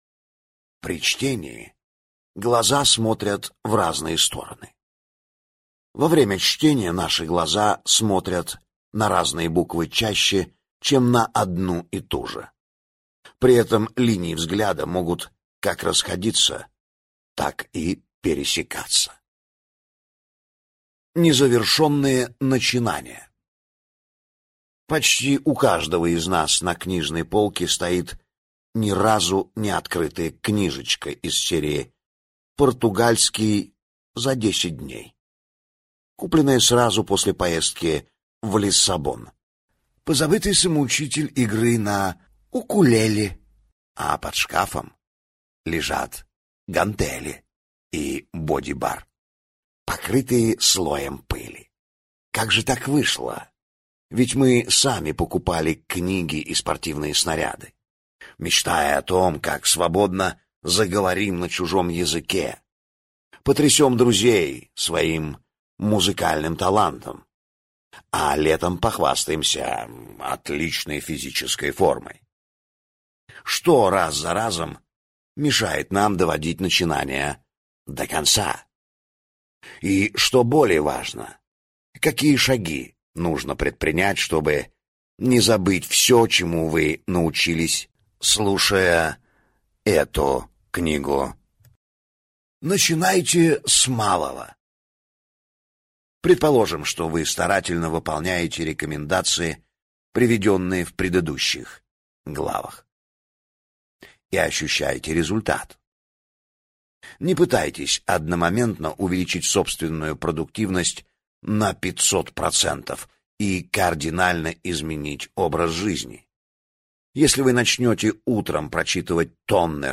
Аудиокнига Скорочтение и запоминание. Знания, которые не займут много места | Библиотека аудиокниг